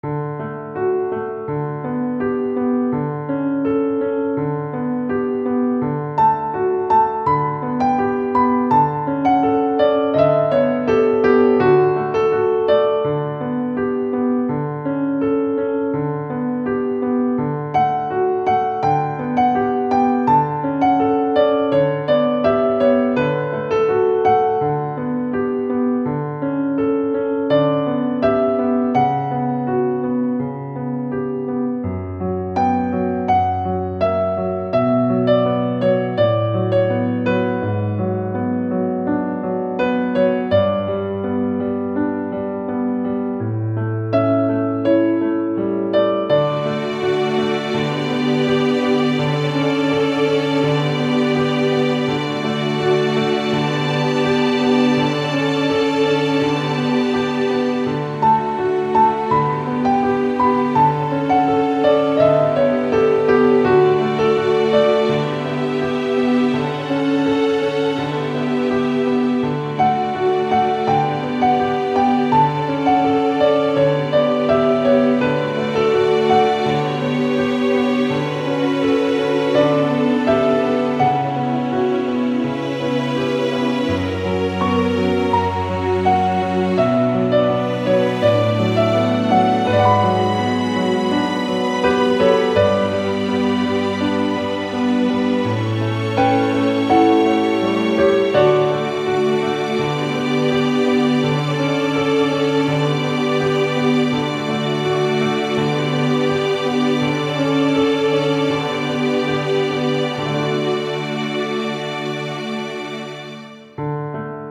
ogg(L) しっとり ピアノ ストリングス
静かなピアノ。